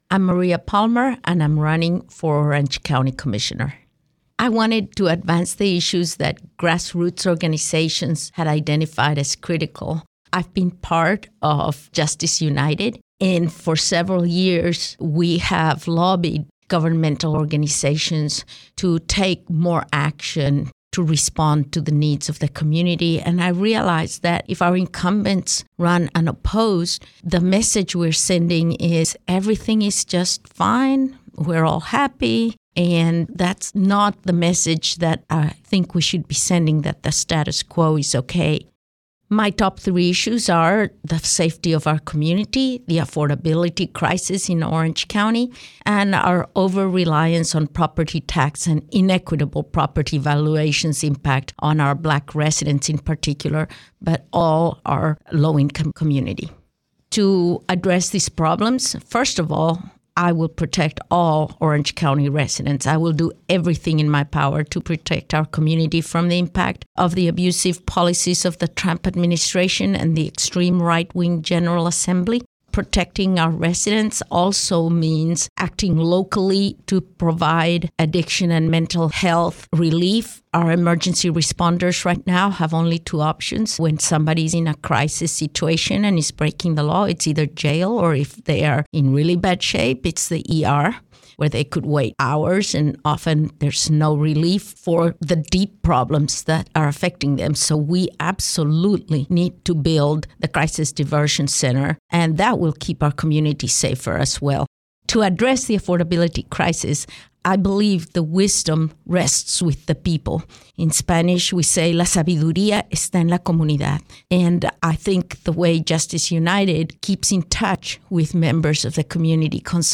97.9 The Hill spoke with each Democrat candidate, asking these questions that are reflected in the recorded responses: